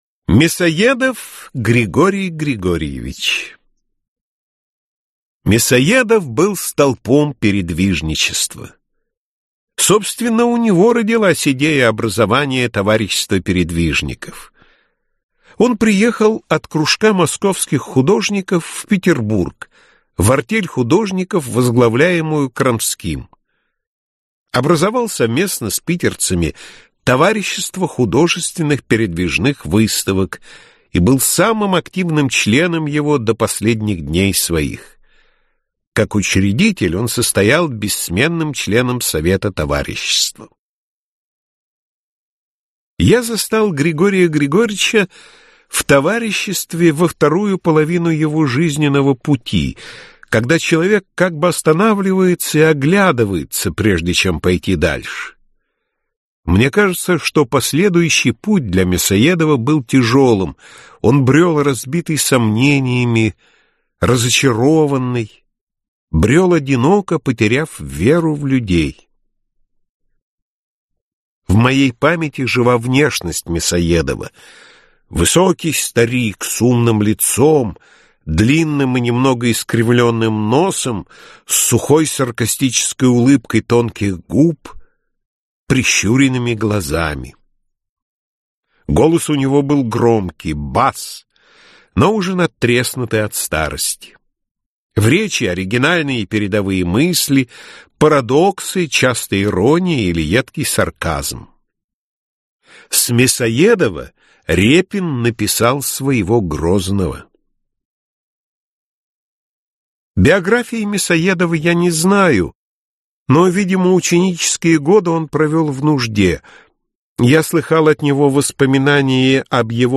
Аудиокнига Рассказы о русских художниках-передвижниках | Библиотека аудиокниг